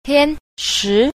4. 天時 – tiān shí – thiên thời
tian_shi.mp3